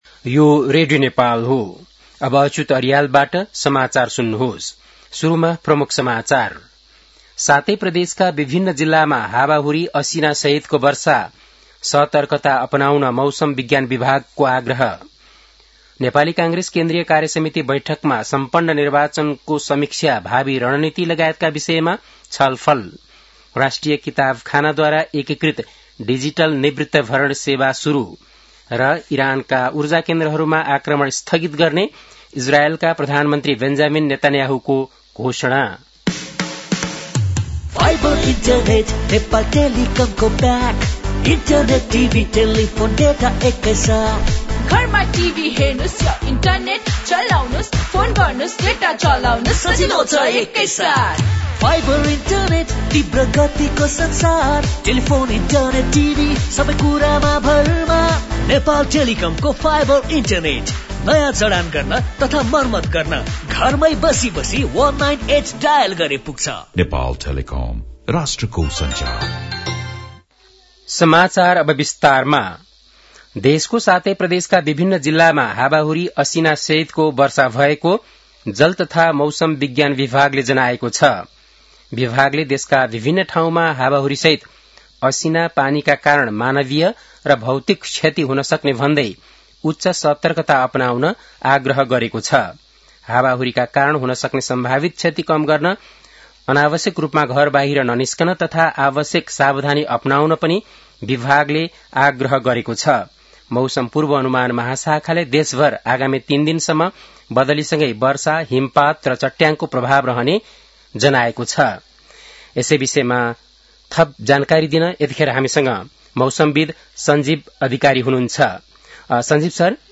बेलुकी ७ बजेको नेपाली समाचार : ६ चैत , २०८२
7-pm-nepali-news-12-06.mp3